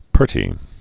(pûrtē)